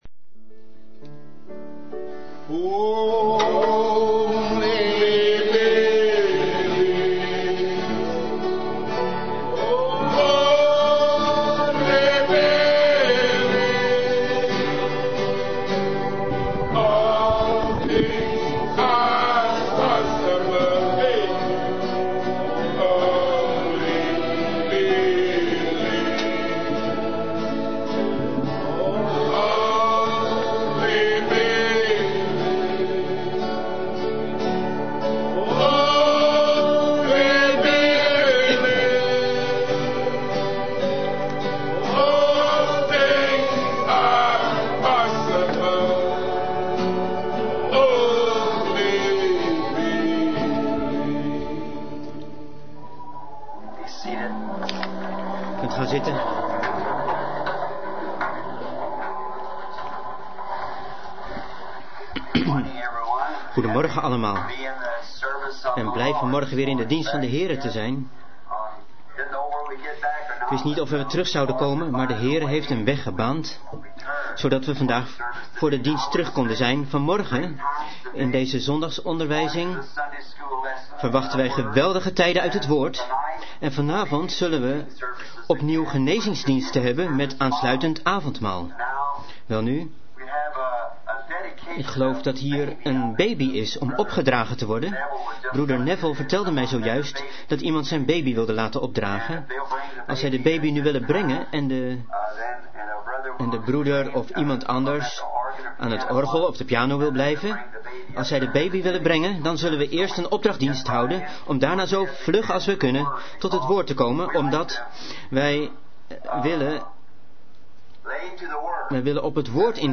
Vertaalde prediking "Token" door William Marrion Branham te Branham Tabernacle, Jeffersonville, Indiana, USA, 's ochtends op zondag 01 september 1963